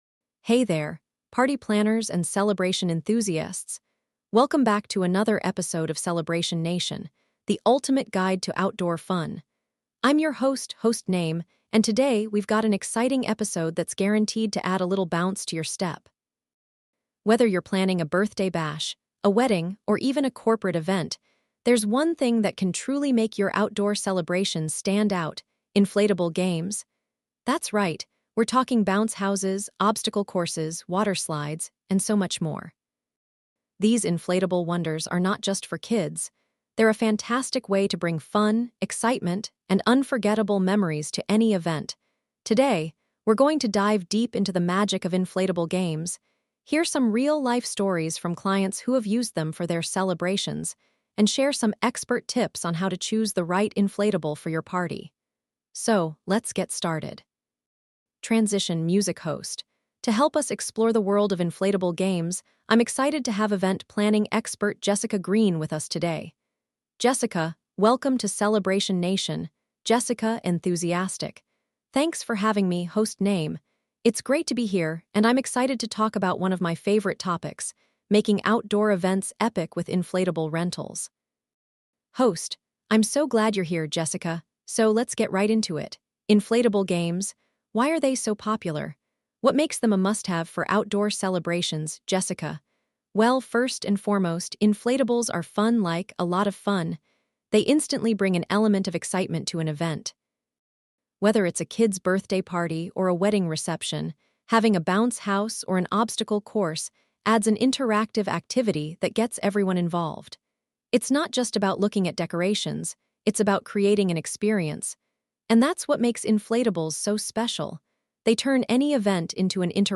We’ll also hear from a recent bride who incorporated a wedding-themed inflatable into her celebration and learn how it became the highlight of her big day.